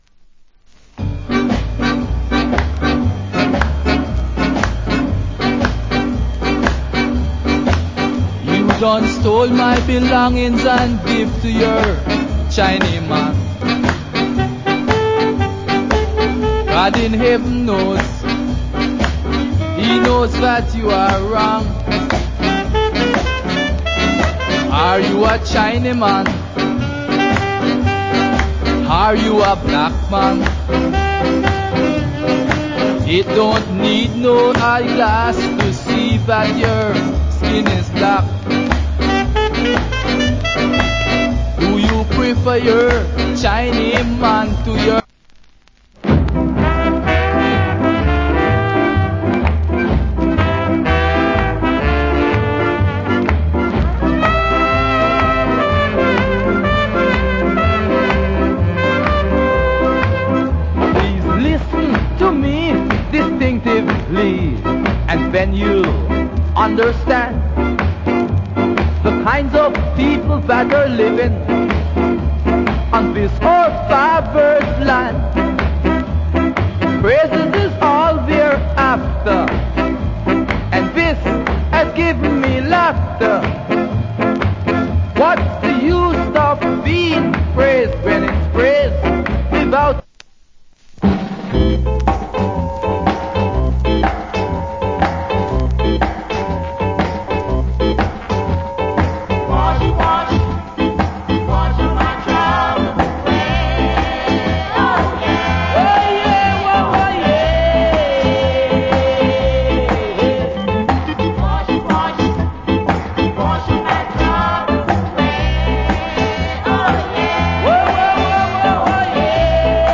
FOUNDATION SKA
Nice Ska Vocal Compilation Album.
60's